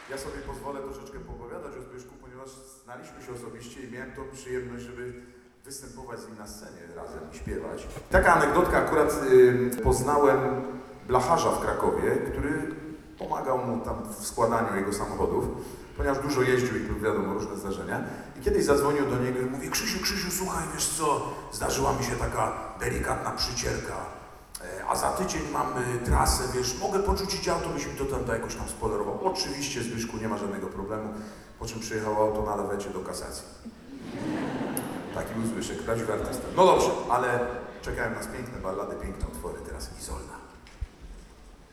Suwałki – Sala im. Andrzeja Wajdy Suwalskiego Ośrodka Kultury – Filharmonia Suwałk – koncert piosenek Zbigniewa Wodeckiego